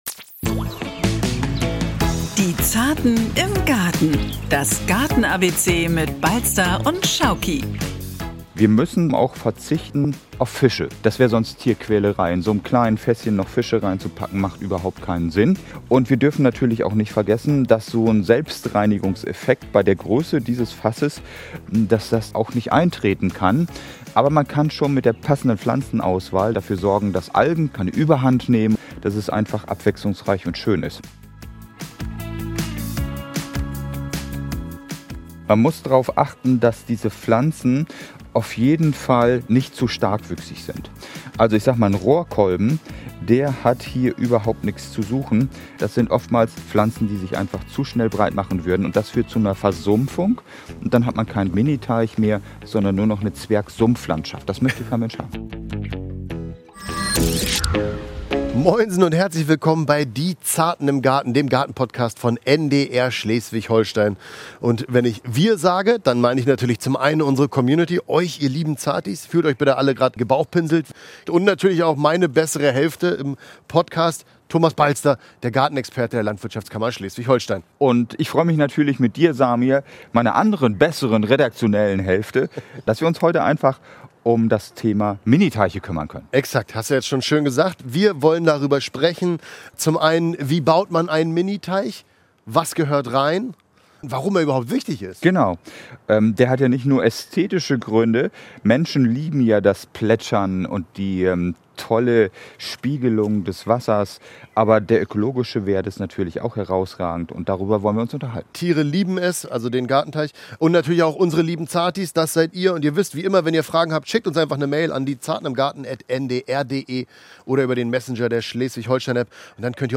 Nachrichten 06:00 Uhr - 15.07.2022